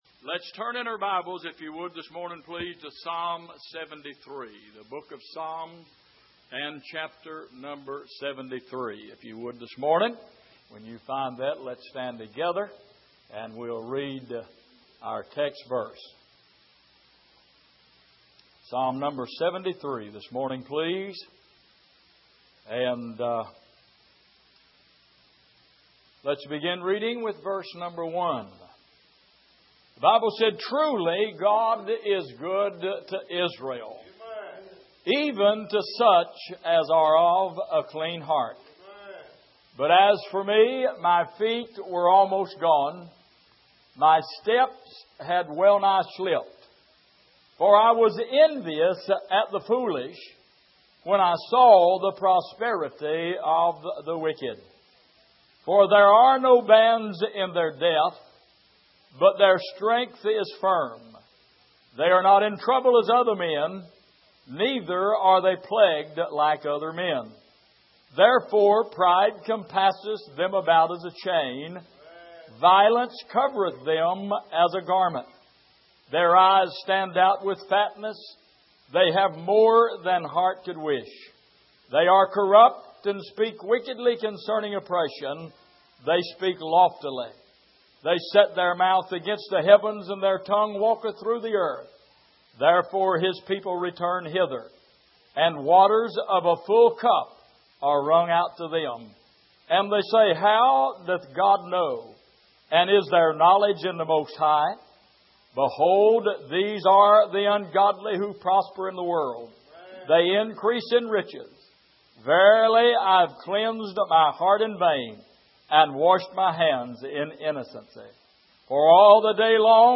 Passage: Psalm 73:1-7 Service: Sunday Morning